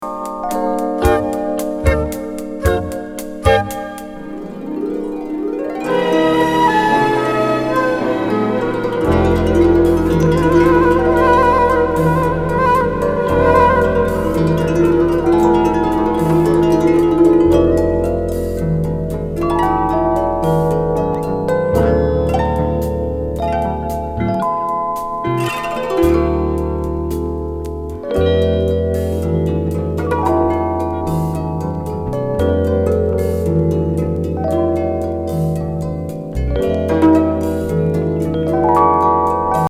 日系フュージョン・グループ
琴や尺八を大々的に取り入れたエキゾチック・グルーブ。